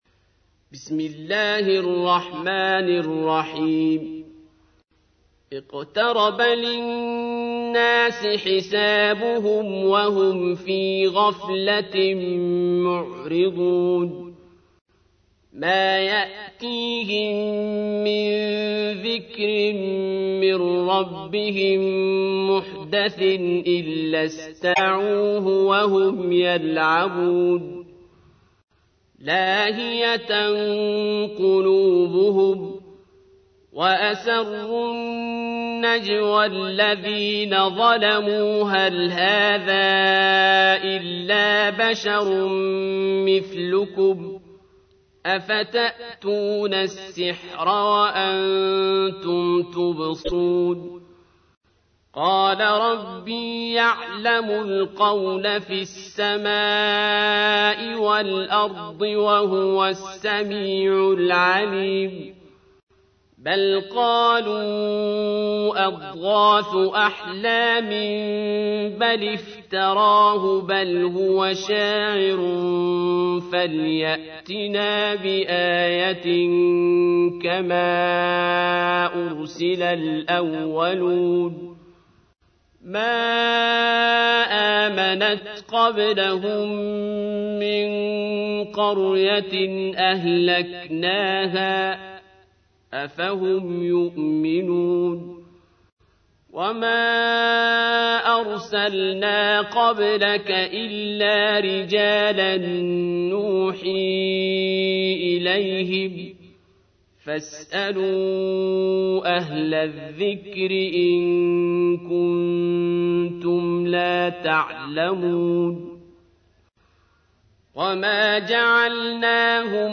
تحميل : 21. سورة الأنبياء / القارئ عبد الباسط عبد الصمد / القرآن الكريم / موقع يا حسين